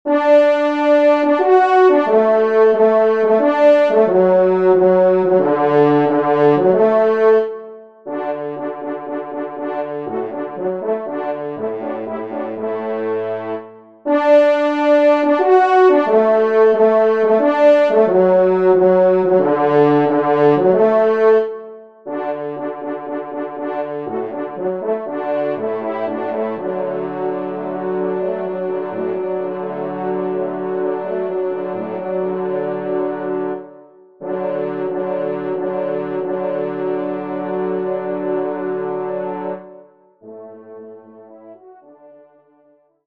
Genre : Divertissement pour Trompes ou Cors
Trompe 2  (en exergue)